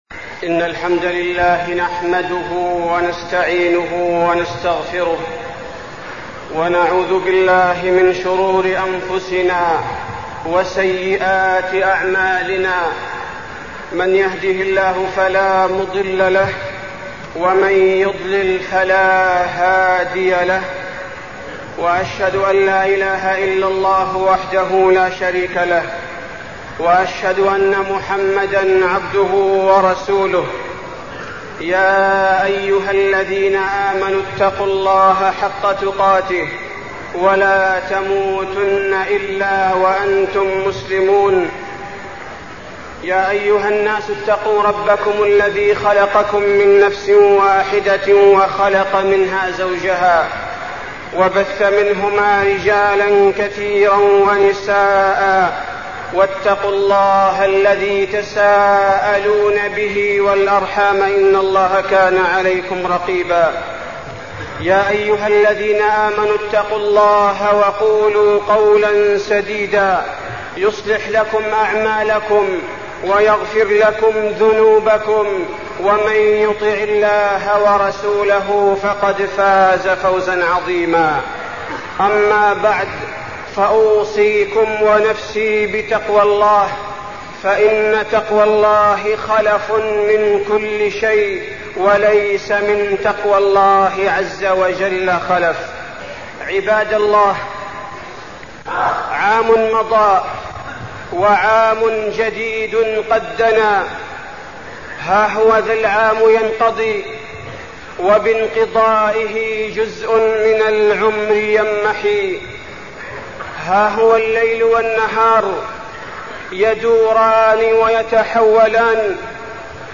تاريخ النشر ٢٧ ذو الحجة ١٤١٥ هـ المكان: المسجد النبوي الشيخ: فضيلة الشيخ عبدالباري الثبيتي فضيلة الشيخ عبدالباري الثبيتي استقبال عام جديد